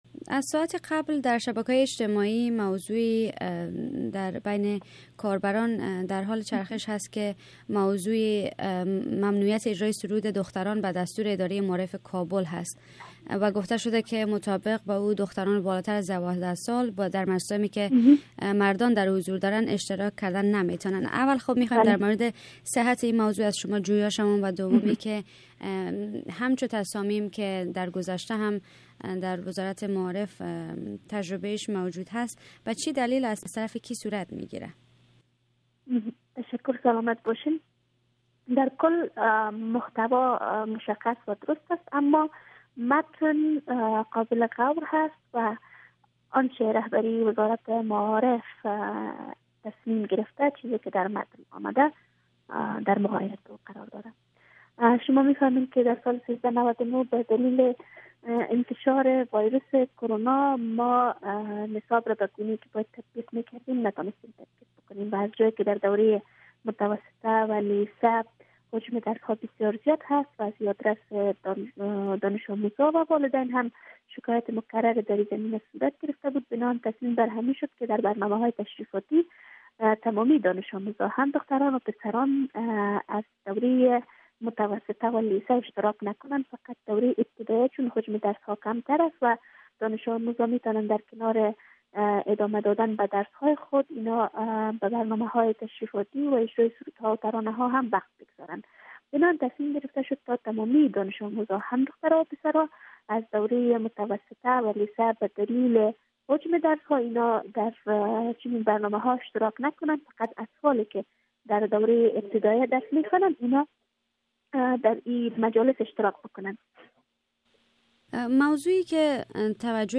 مصاحبۀ